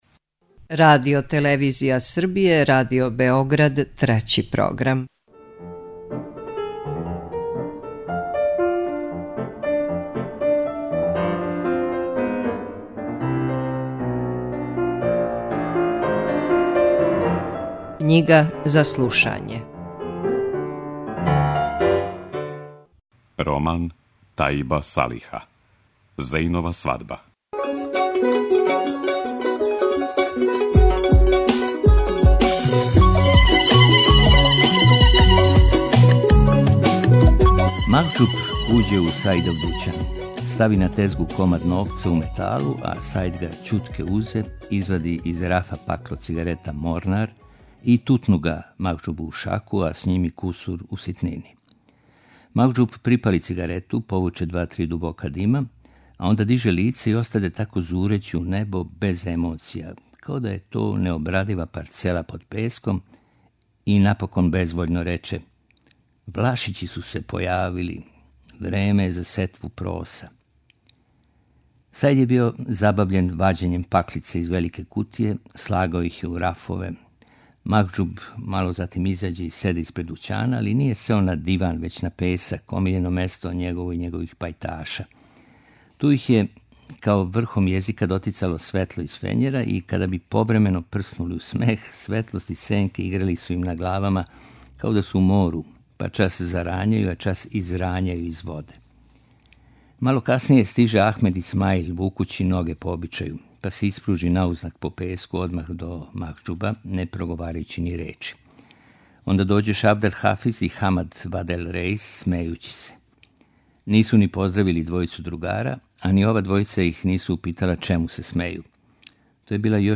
преузми : 9.79 MB Књига за слушање Autor: Трећи програм Циклус „Књига за слушање” на програму је сваког дана, од 23.45 сати.